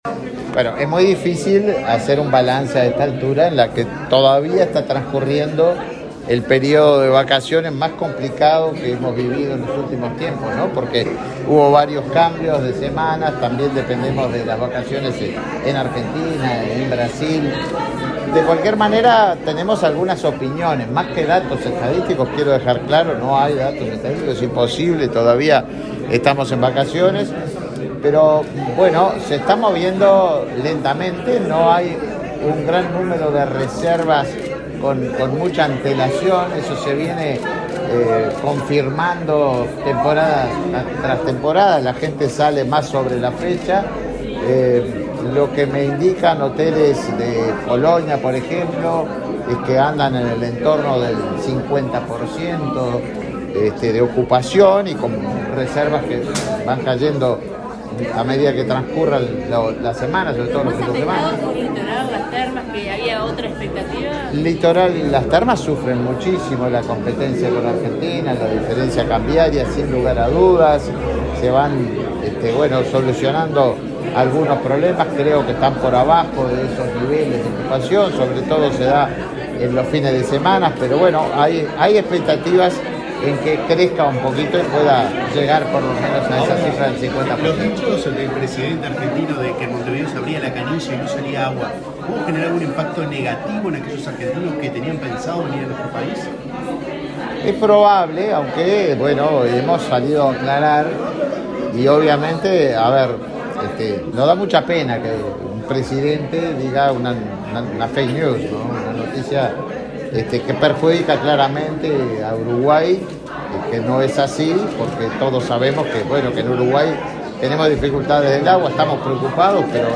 Declaraciones del ministro de Turismo, Tabaré Viera
Declaraciones del ministro de Turismo, Tabaré Viera 12/07/2023 Compartir Facebook X Copiar enlace WhatsApp LinkedIn Este miércoles 12, el ministro de Turismo, Tabaré Viera, participó en el lanzamiento de la octava edición del Festival Binacional de Gastronomía, que se desarrollará en la plaza internacional de Rivera, entre el 28 de julio y el 5 de agosto. Luego dialogó con la prensa.